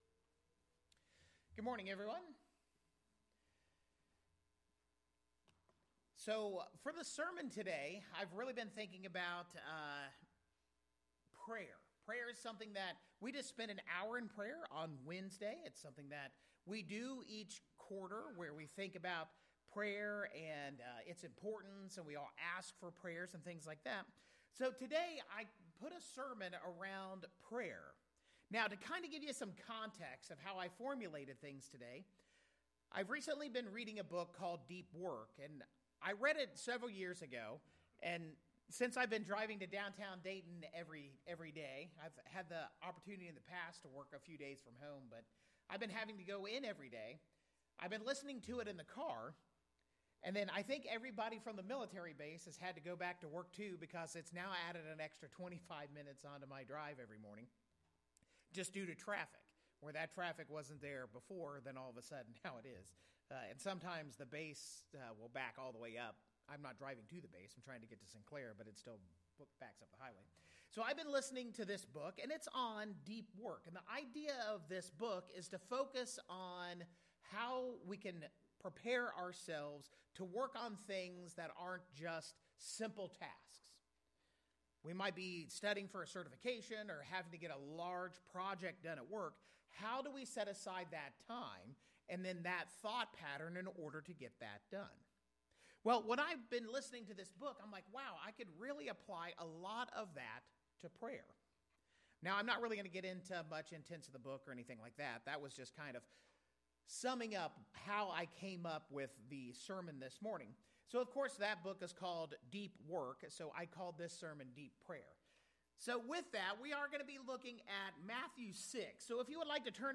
Using Matthew 6 as a framework, this sermon offers practical steps, biblical examples, and a call to spiritual depth through consistent communication with God.